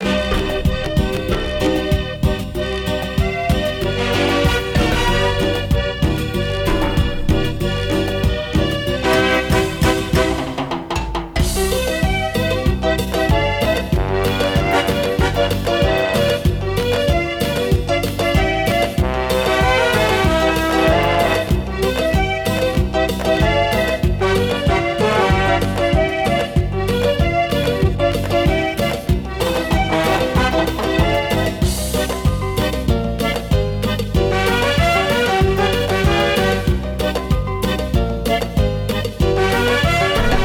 melodía
repetitivo
ritmo
salsa
sintetizador
Sonidos: Música